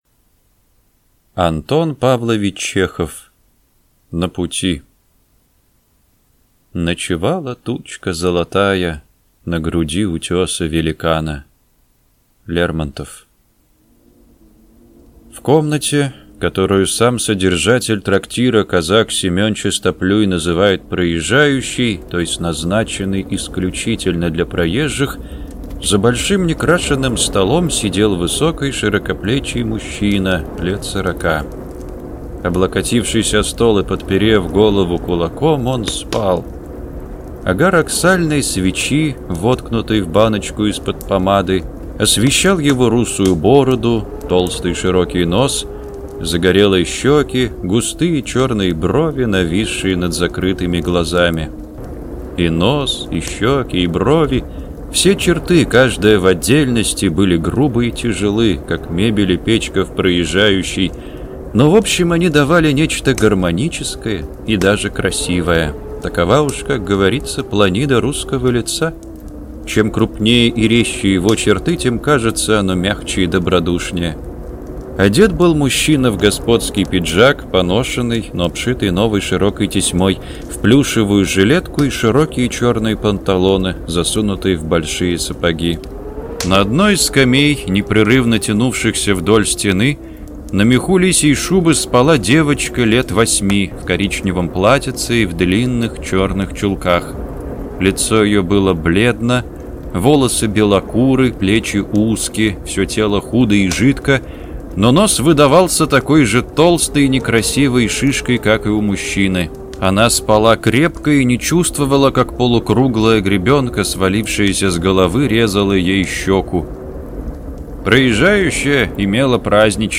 Аудиокнига На пути | Библиотека аудиокниг